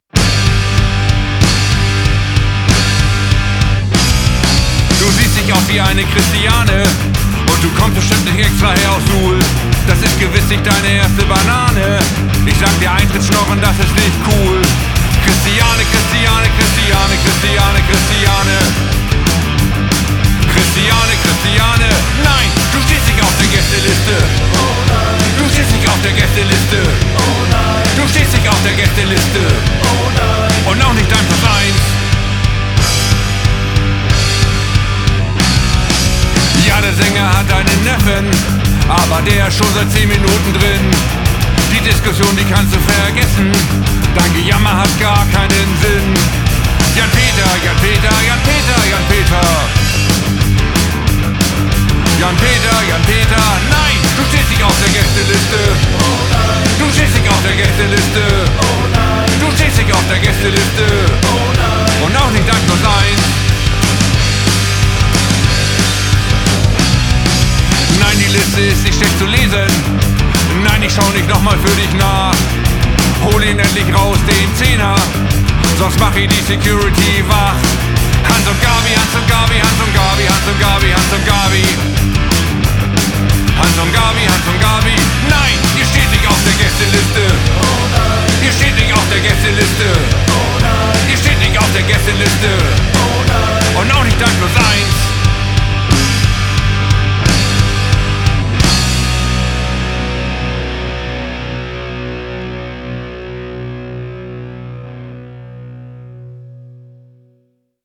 Stil: Deutschpunk